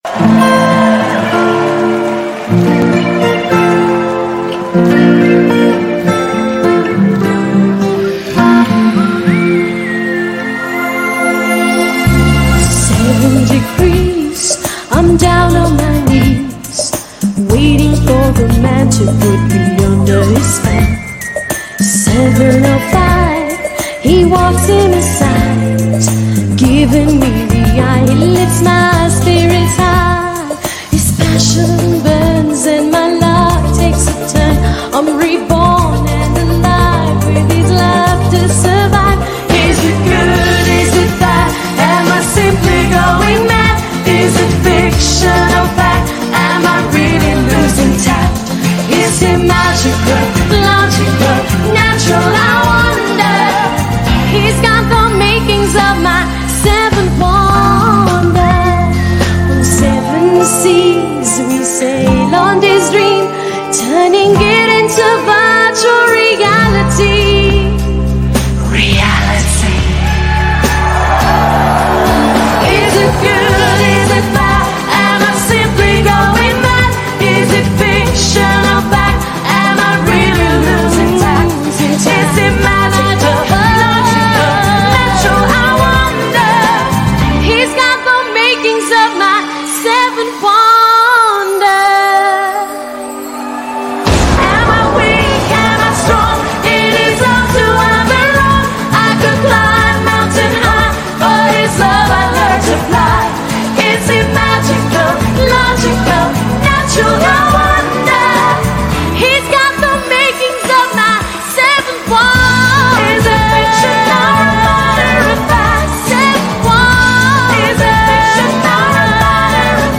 BPM52-210
Audio QualityPerfect (High Quality)
*The real minimum BPM of this song is 52.5